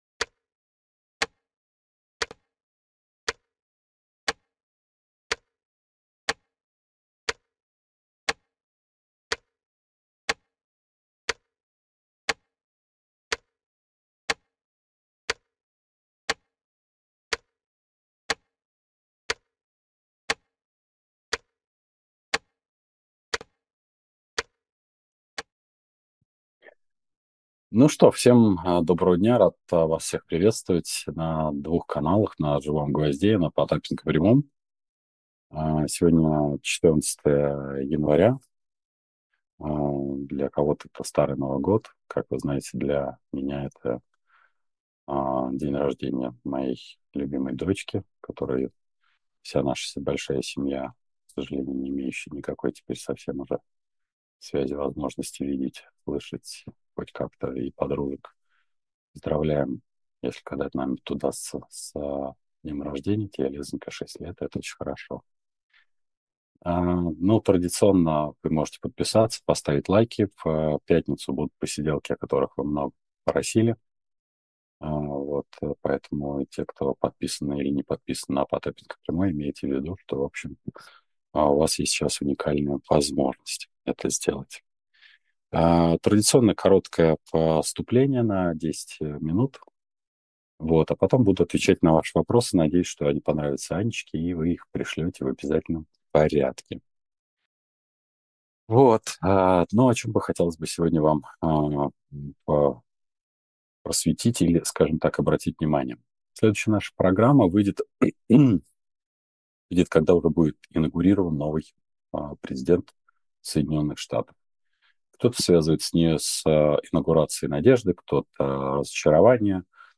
Эфир ведёт Дмитрий Потапенко